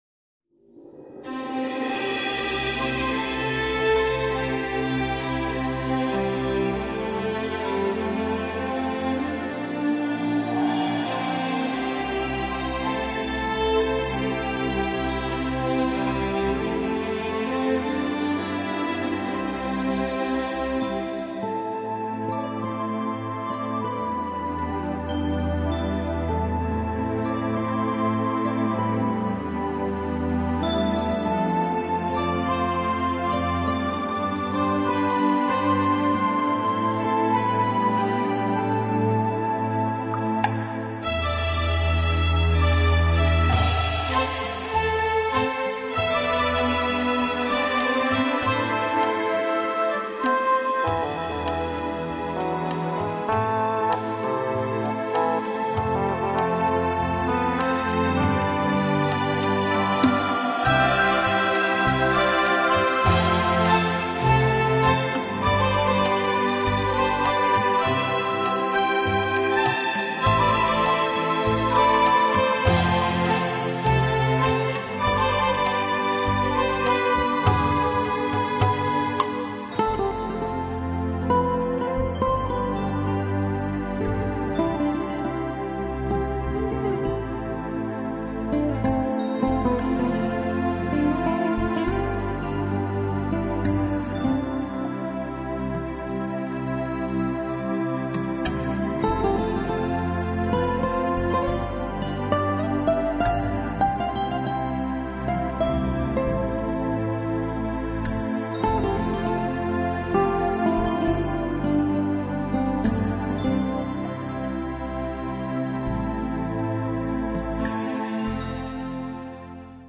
弦乐的平和安详，仿佛洒落满怀阳光，舒畅遍体。